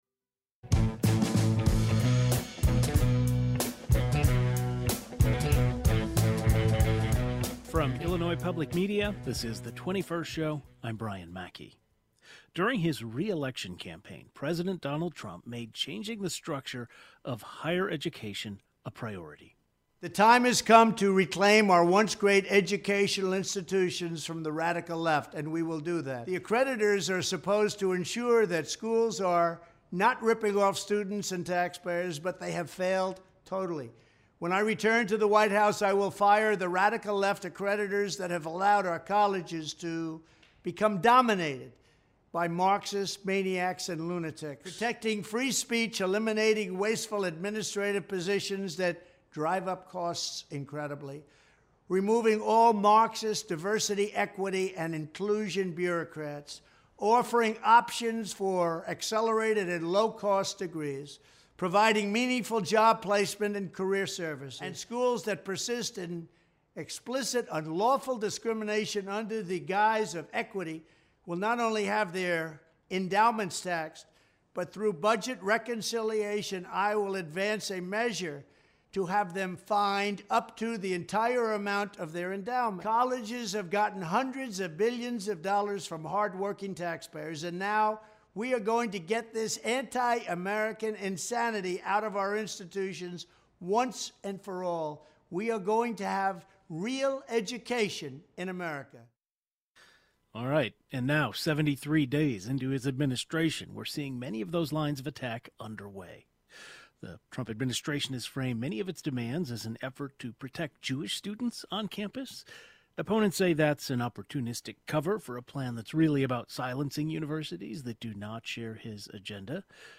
Regardless of the cause, billions of dollars in federal funding is at stake ... often for medical and scientific research. A panel of journalists who cover higher education join the program today to discuss how universities in Illinois could be impacted by potential funding cuts.